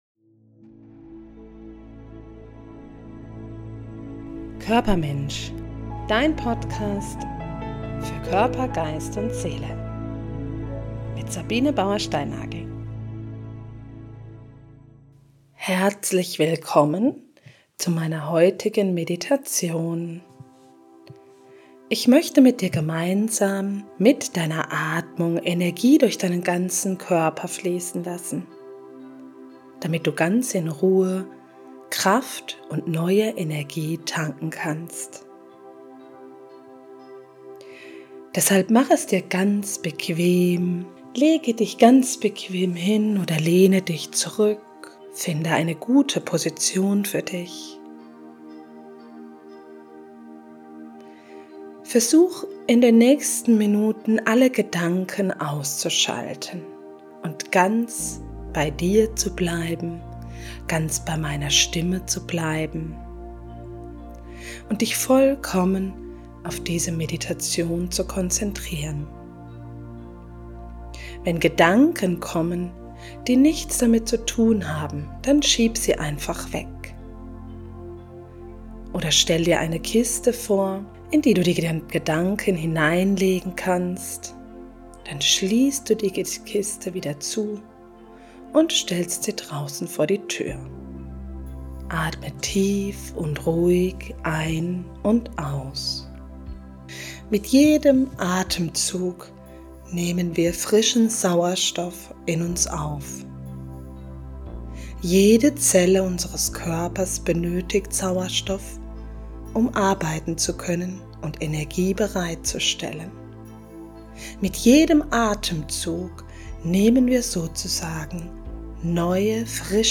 Energie-Meditation.mp3